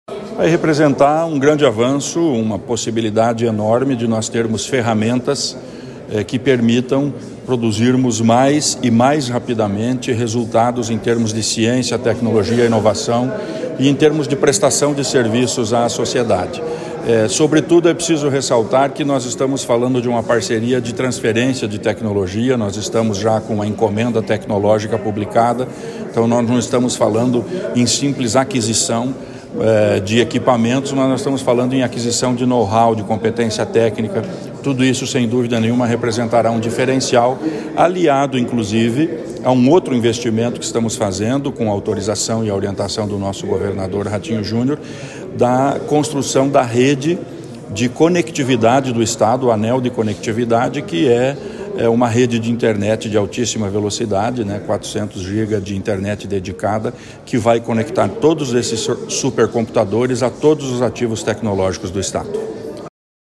Sonora do secretário da Ciência, Tecnologia e Ensino Superior, Aldo Bona, sobre reunião com novo embaixador da Índia sobre parcerias em inovação e tecnologia